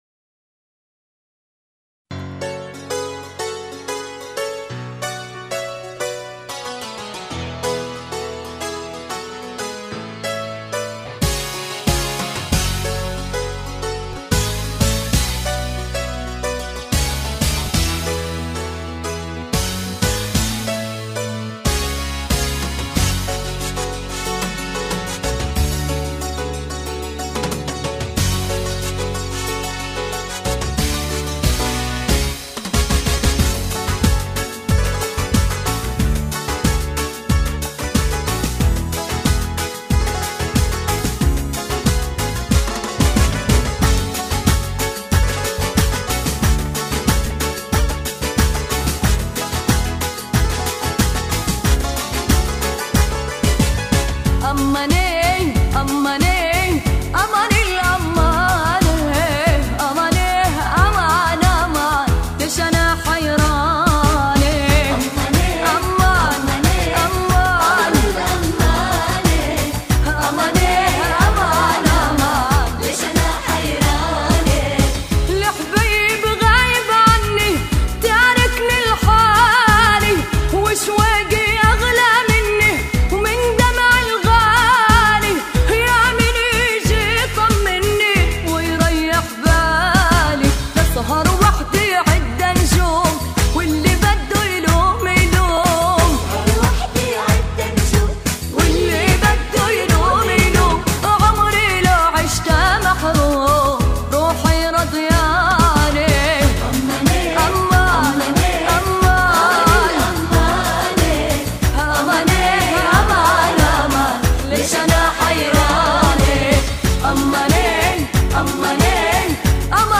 یک آهنگ نوستالژی و خاطره انگیز